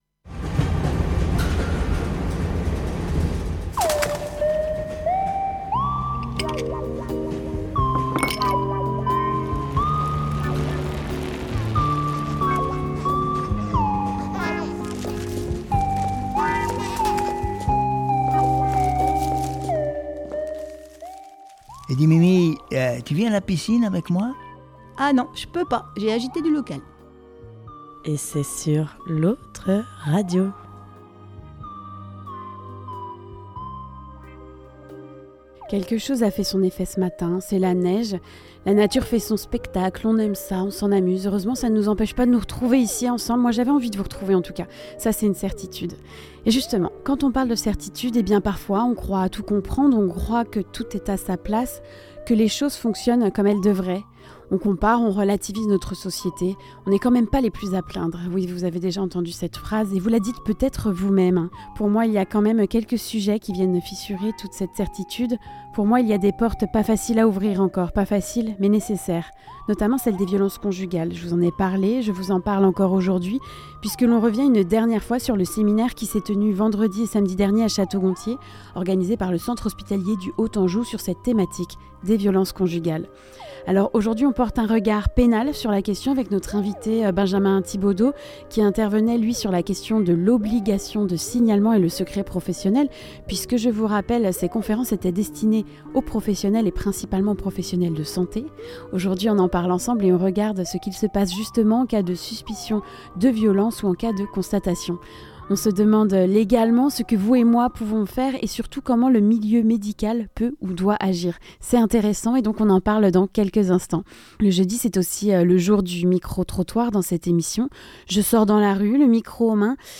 Le micro trottoir de la semaine